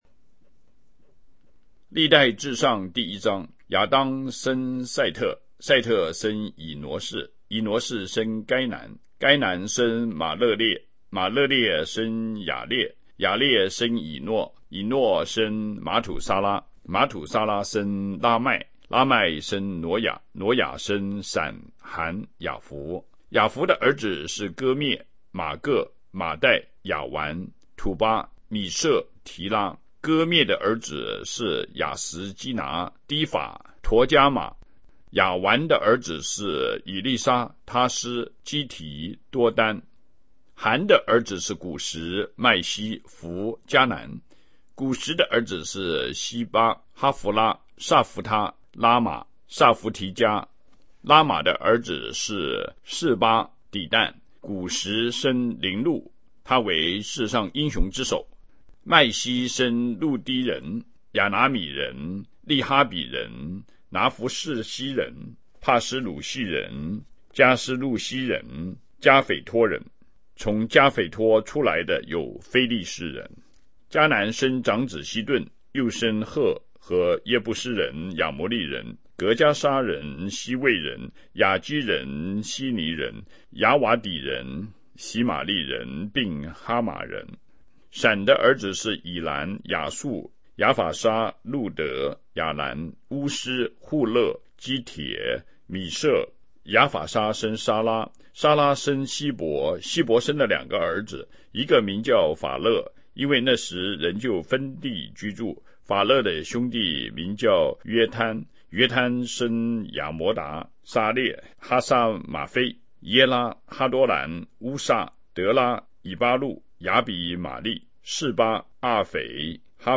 每日讀經 歷代志上1-2 亚当生塞特、塞特生以挪士、以挪士生该南、该南生玛勒列、玛勒列生雅列、雅列生以诺、以诺生玛土撒拉、玛土撒拉生拉麦、拉麥生挪亞、挪亞生閃、含、雅弗。
BibleReading531.mp3